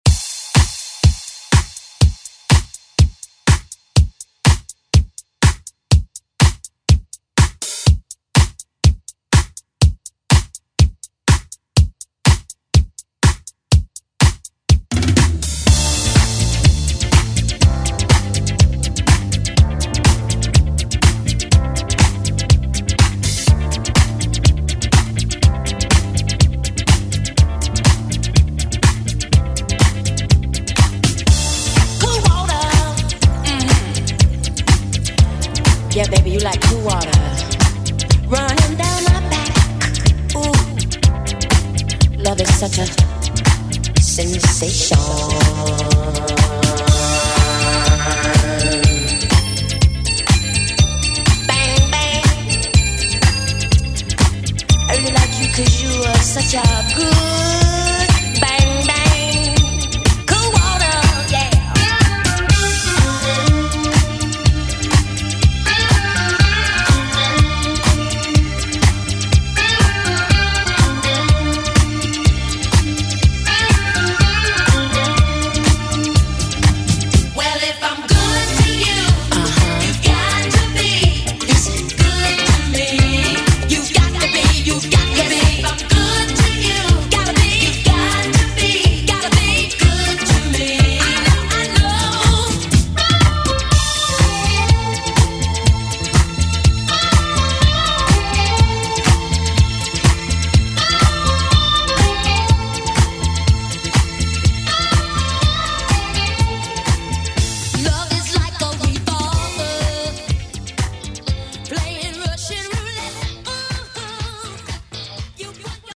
Electronic House Trance Music
Extended Intro Outro
137 bpm